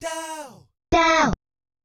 The "Dow" sample used in Wario Land 4, in both its original and compressed sample rates.